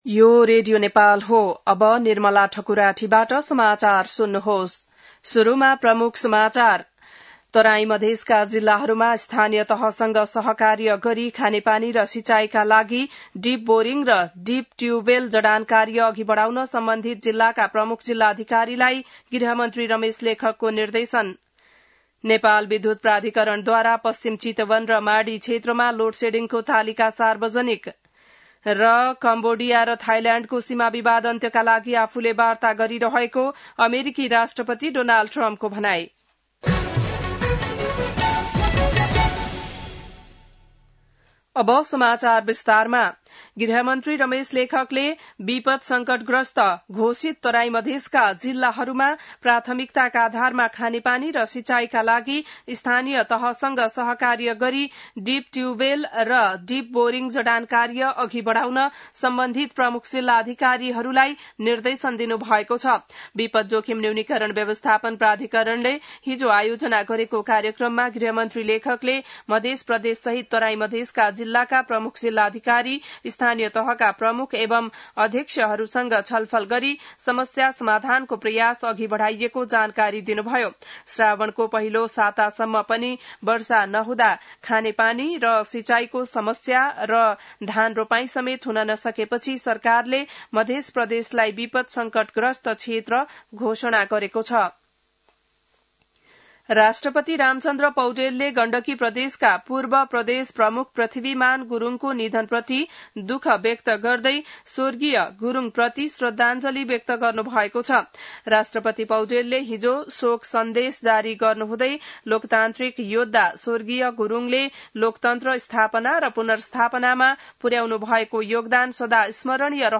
बिहान ९ बजेको नेपाली समाचार : १८ पुष , २०२६